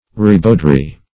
ribaudry - definition of ribaudry - synonyms, pronunciation, spelling from Free Dictionary Search Result for " ribaudry" : The Collaborative International Dictionary of English v.0.48: Ribaudry \Rib"aud*ry\, n. Ribaldry.
ribaudry.mp3